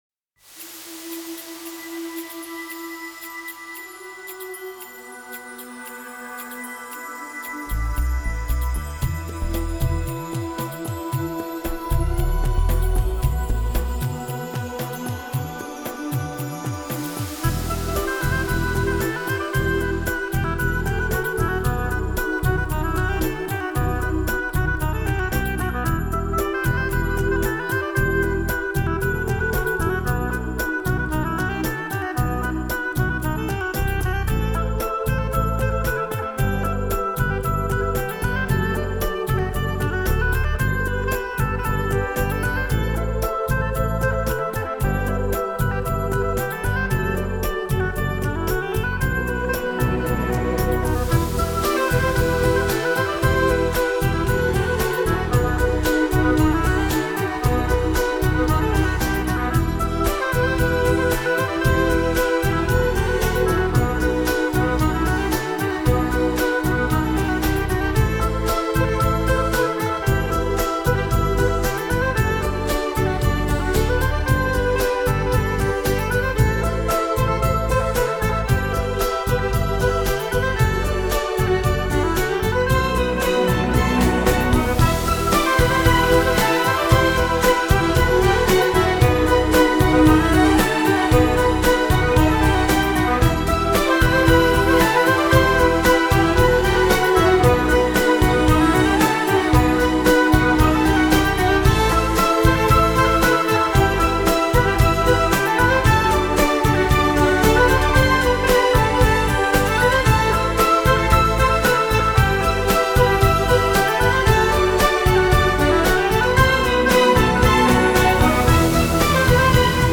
自然、清脆、响亮，这是我的印象!